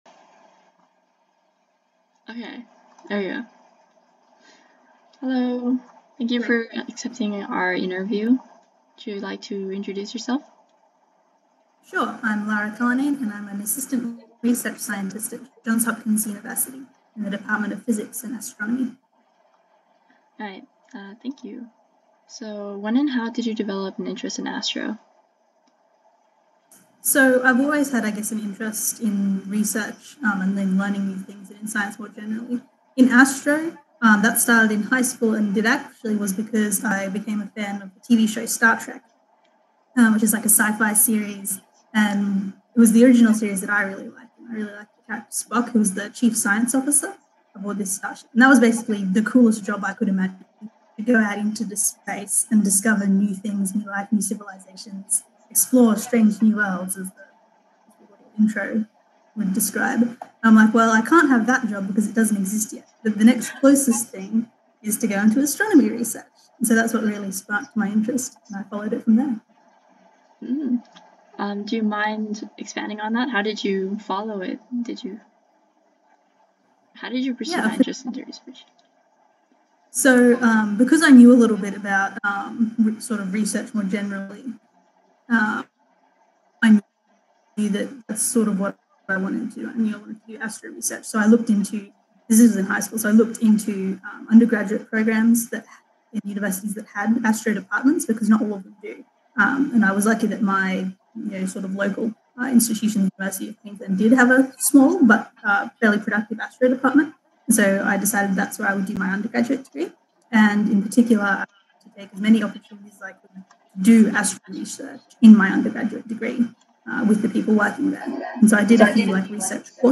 Path to the Stars: Interview with Astrophysics Researcher